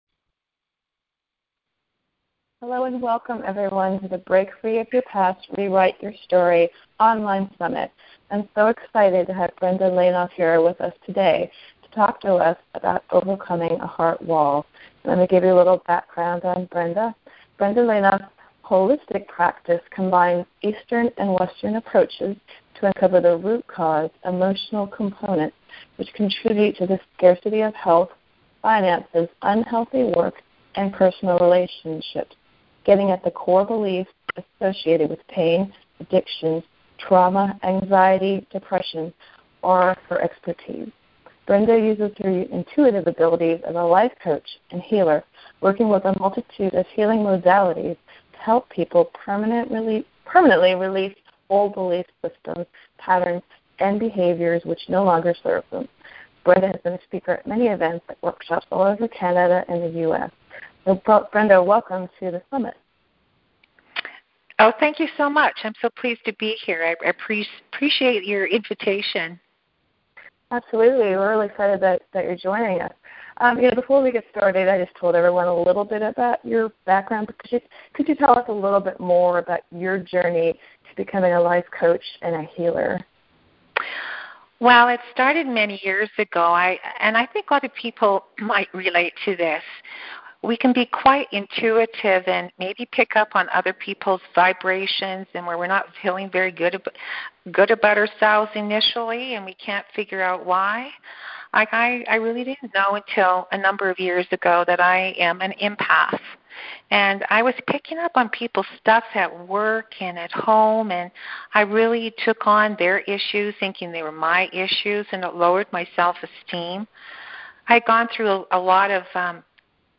Teleseminar Break Free of Your Past and Rewrite Your Story